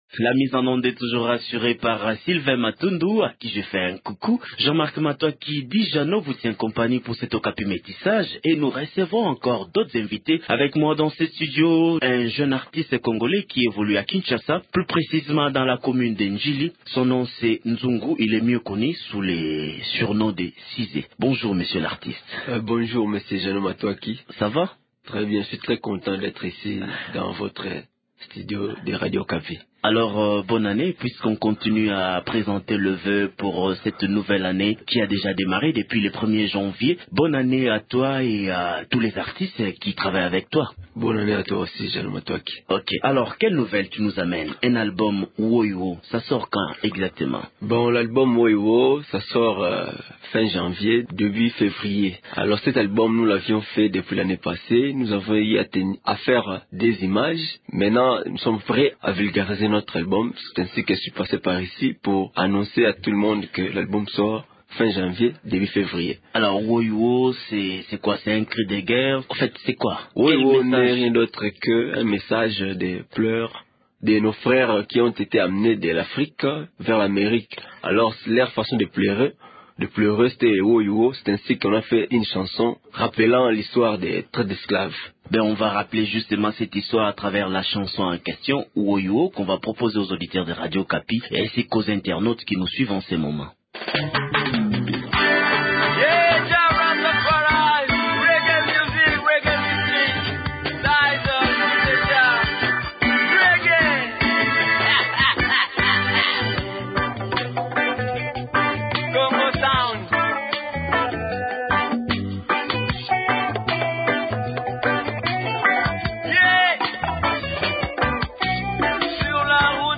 Il exploite le style reggae, avec un clin d’œil à la rumba congolaise.